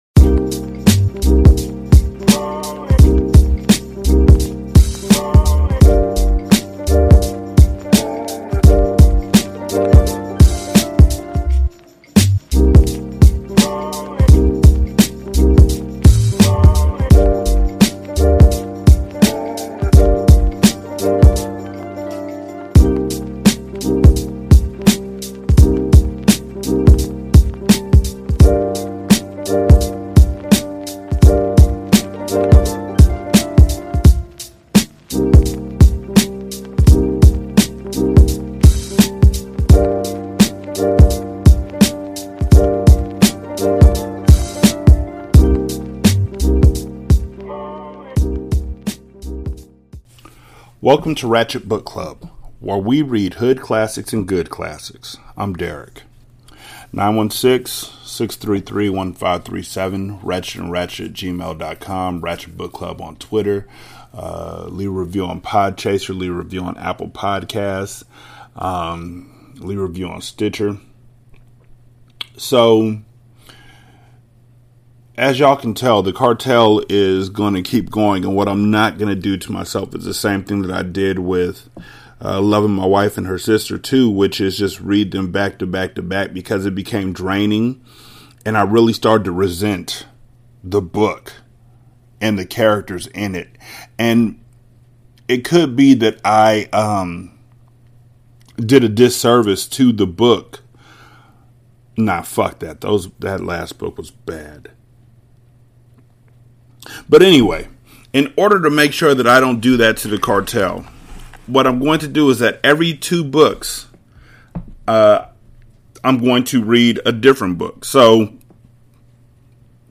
In this episode, I read another chapter from Jeffery Deaver's Twisted. The chapter is called The Weekender.